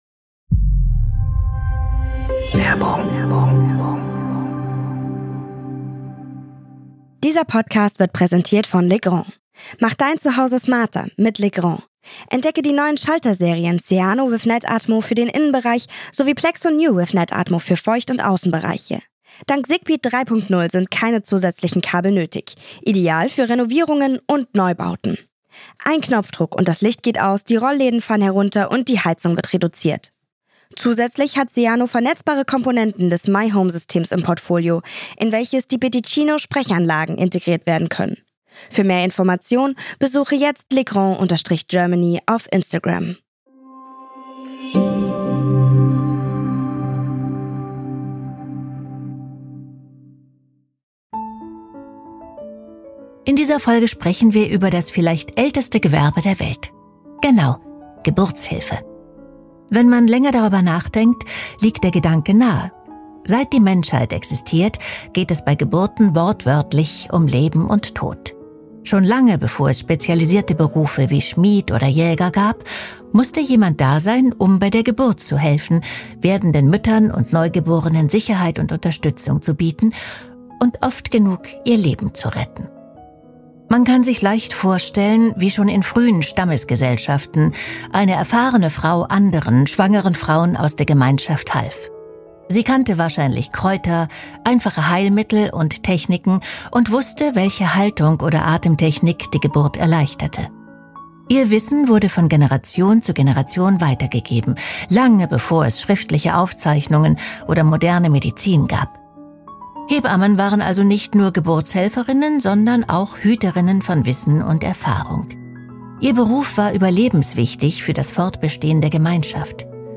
Andrea Sawatzki erzählt von der Geburt eines Königs und den Anfängen der modernen Geburtsmedizin – und wie Louyse Bourgeois beides möglich gemacht hat.